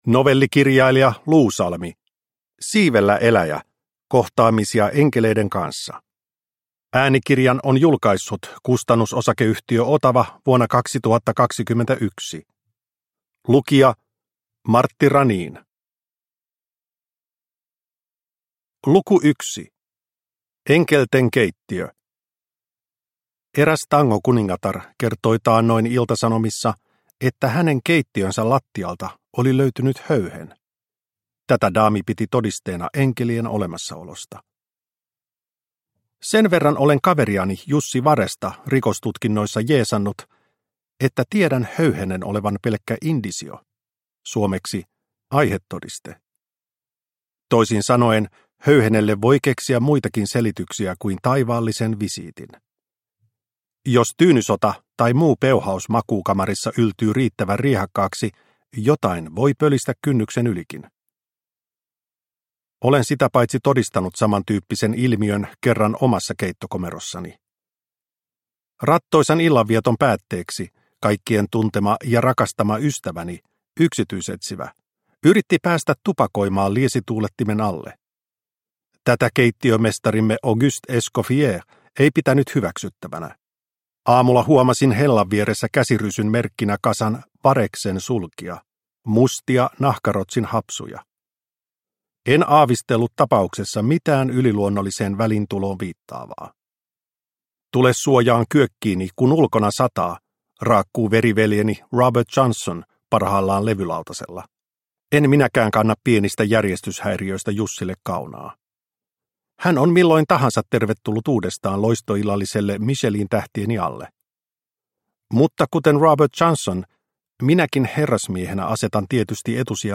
Siivellä eläjä – Ljudbok